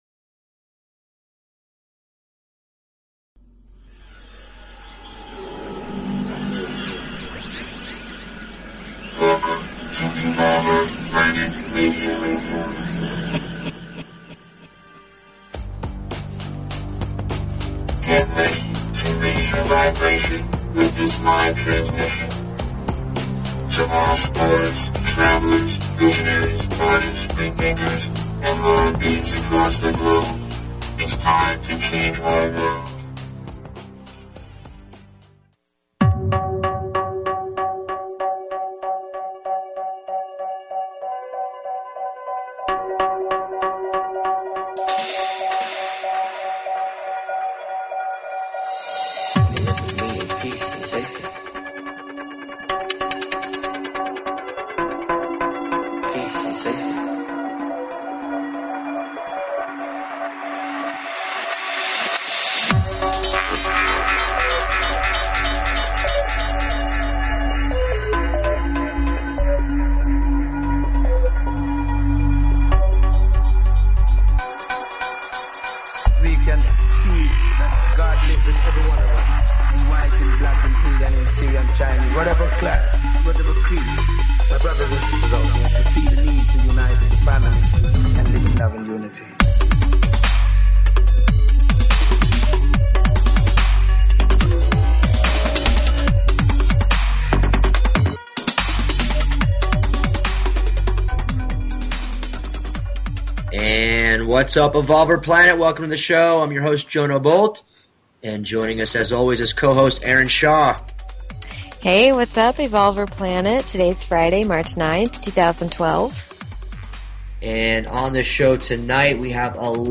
Talk Show Episode, Audio Podcast, Evolver_Planet_Radio and Courtesy of BBS Radio on , show guests , about , categorized as